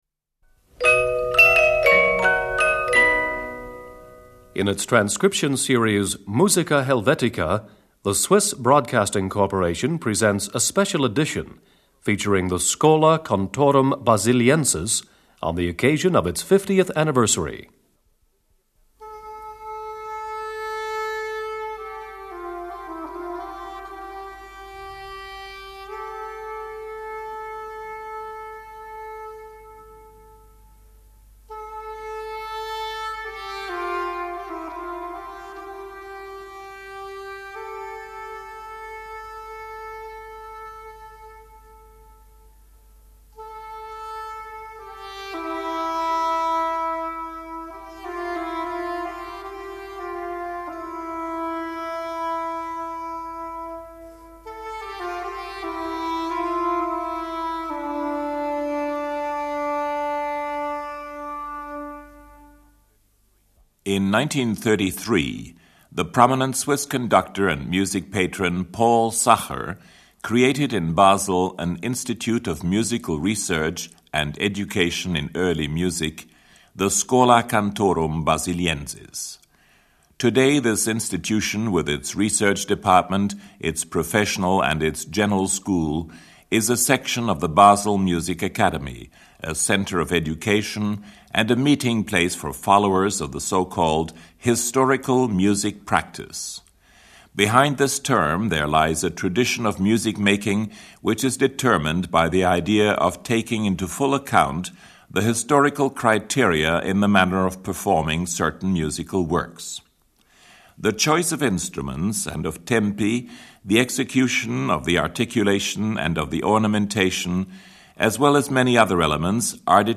shawm 2.
As sung during Easter Mass at Notre Dame Cathedral Paris (ca. 1200)
Choral Ensemble of the Schola Cantorum Basiliensis
Montserrat Figueras, vocal soloist
Jordi Savall, viola da gamba
harpischord 5.
cornetto
positive organ 6.